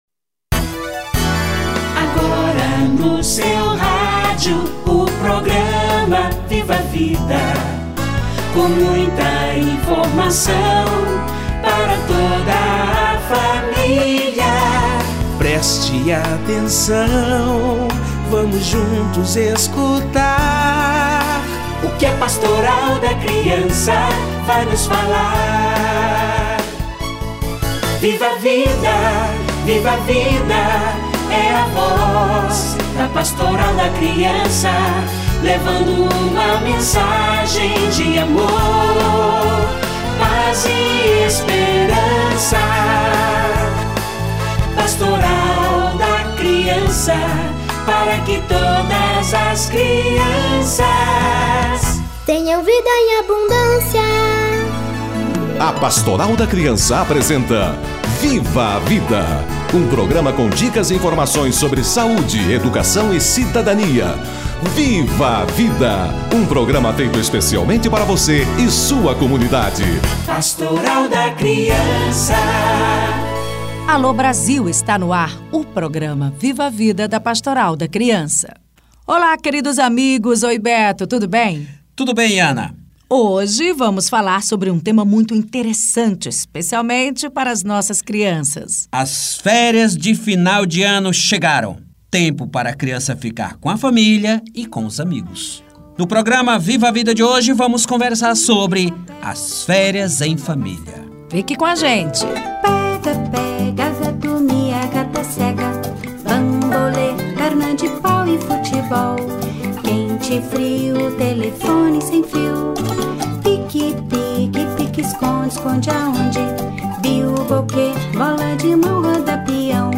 Férias em família - Entrevista